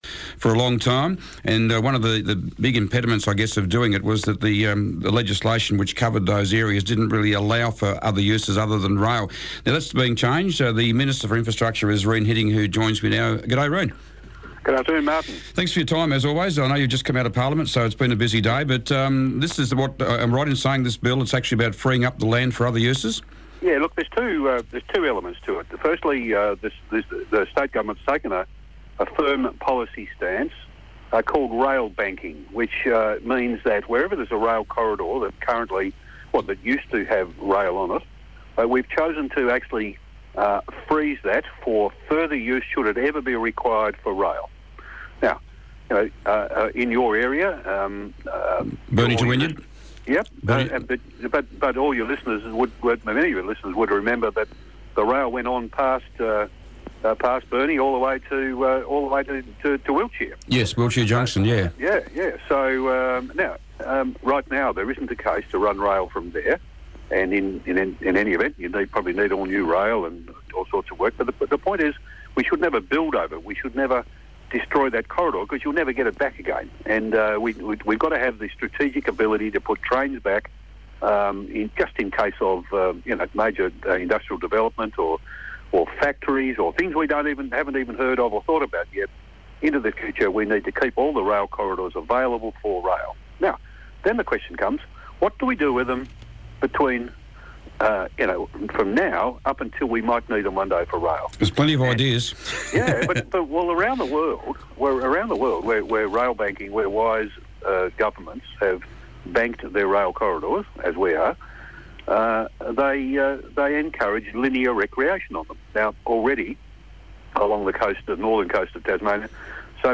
spoke to Infrastructure Minister Rene Hidding about the aims and objectives of the new legislation